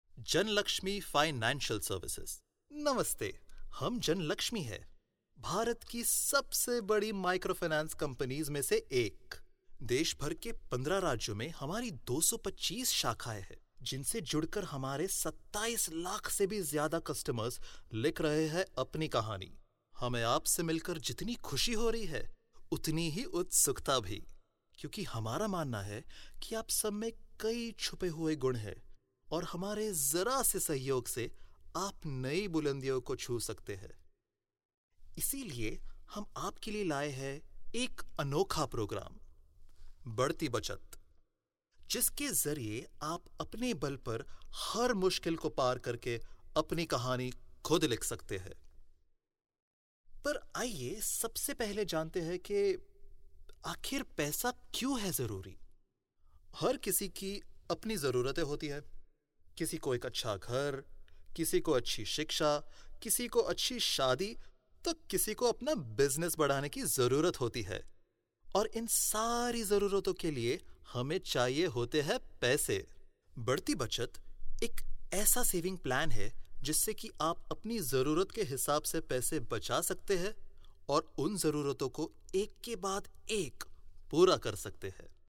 Has a young voice which suits narrative style.
Sprechprobe: Industrie (Muttersprache):